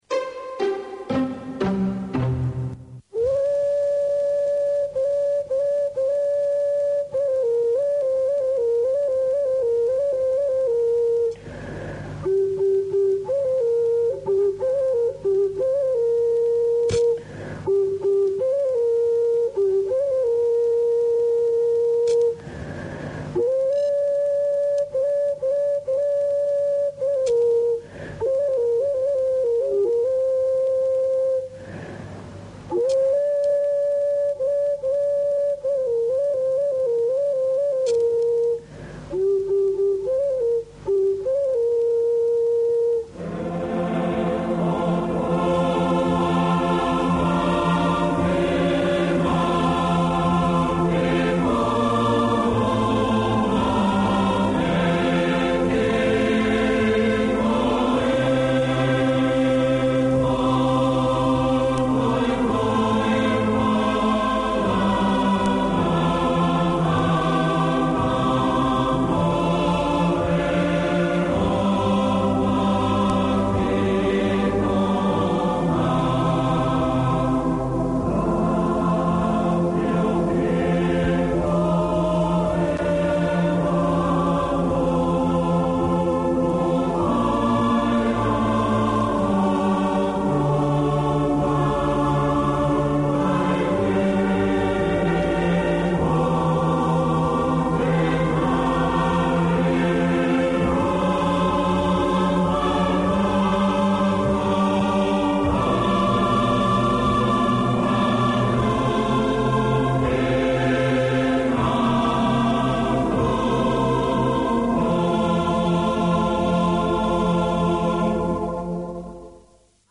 The hot Tongan News show, Akiheuho canvases current affairs of concern to Tongans airing in-depth interviews with Tongan figureheads, academics and successful Tongans from all walks of life. Covering the world and Pasefika from a Tongan viewpoint.